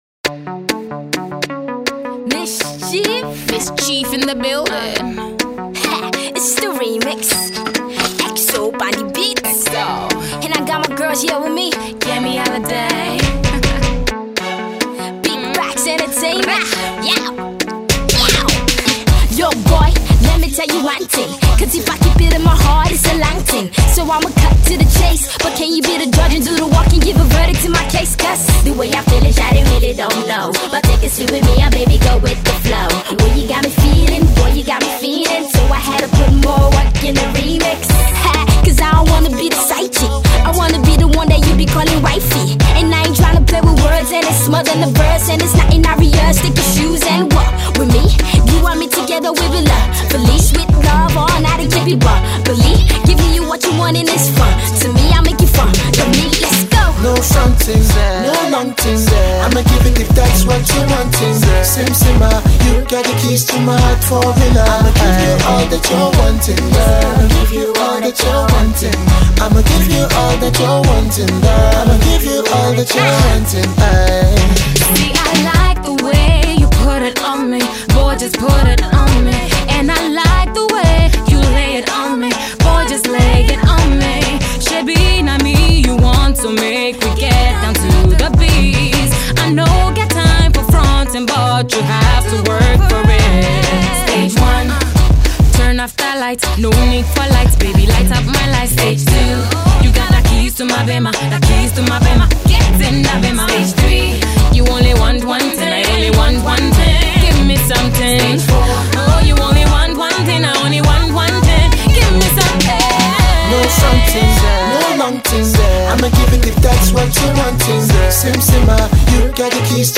the all female cast